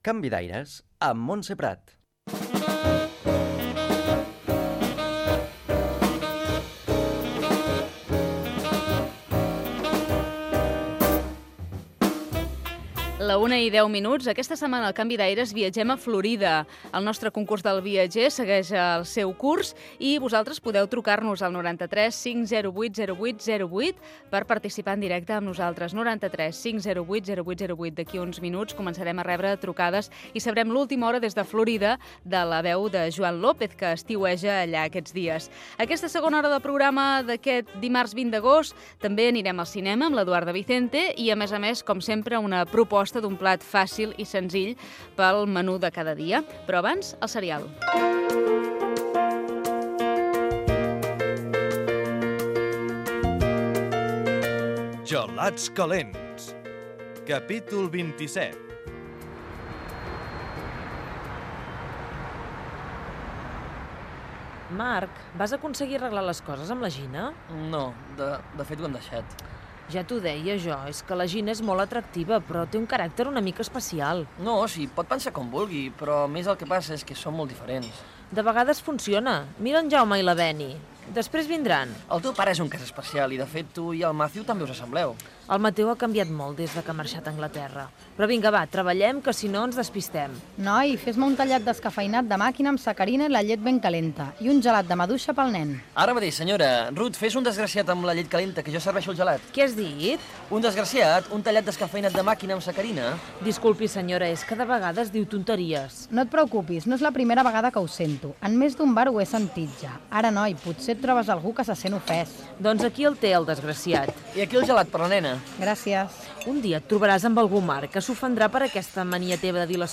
Sumari de la segona hora del programa i fragment del serial "Gelats calents"
Entreteniment